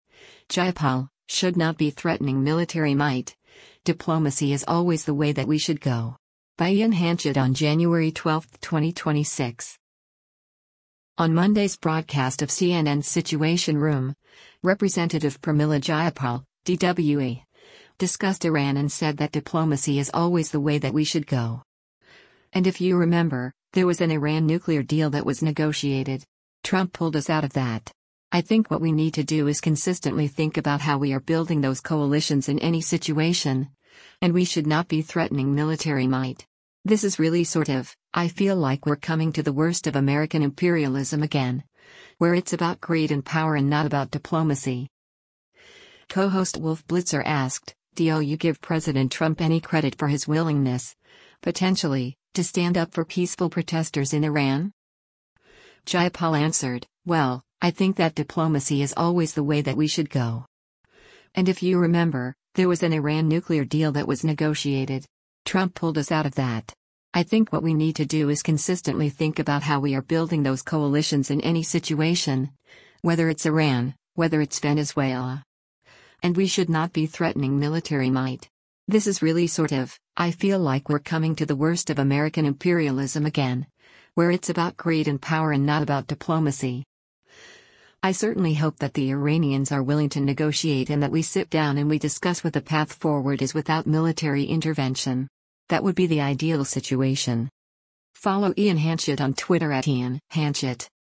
Co-host Wolf Blitzer asked, “[D]o you give President Trump any credit for his willingness, potentially, to stand up for peaceful protesters in Iran?”